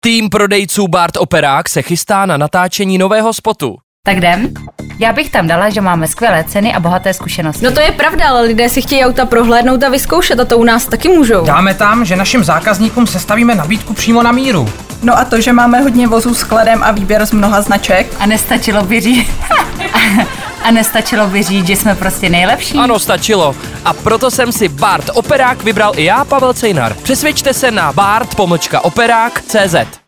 Naši kolegové natočili neobyčejný rádio spot...
V rádiu můžete slyšet spot, na kterém se podíleli naši zaměstnanci.